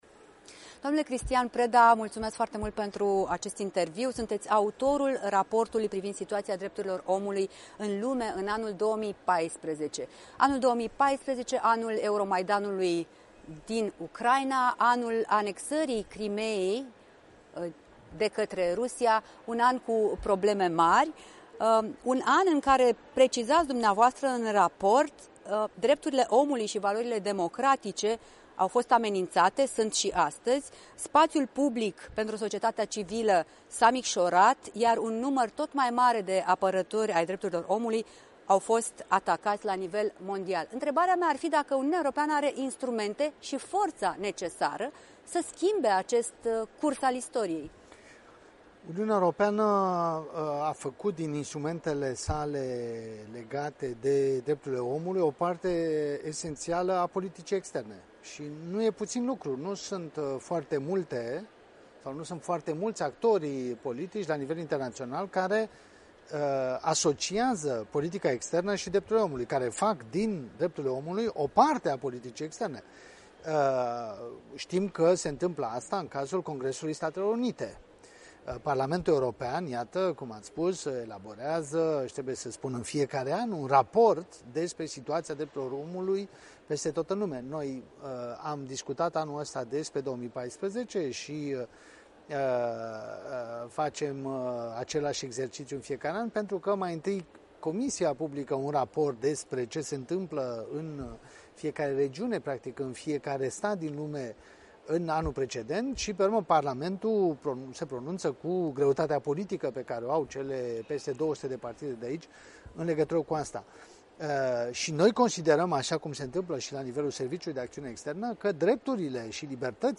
Un interviu în exclusivitate